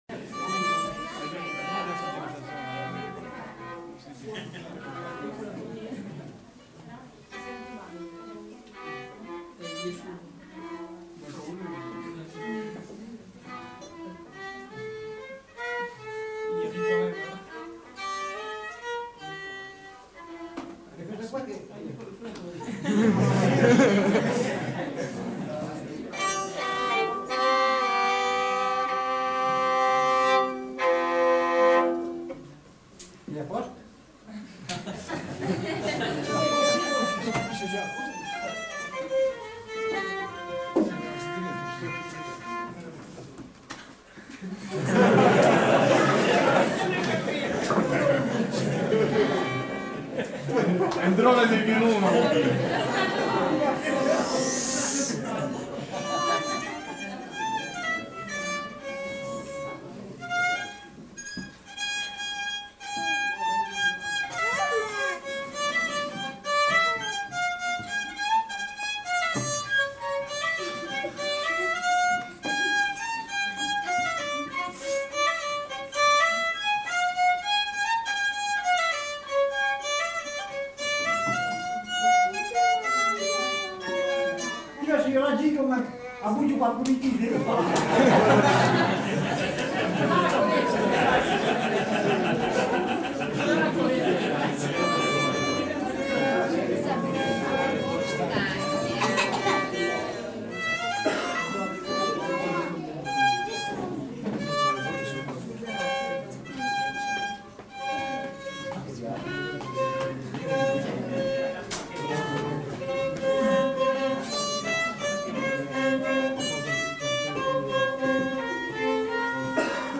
Instrumental. Violon. Val Varaita
Aire culturelle : Val Varaita
Lieu : Bellino
Genre : morceau instrumental
Instrument de musique : violon
Notes consultables : Le joueur de violon n'est pas identifié.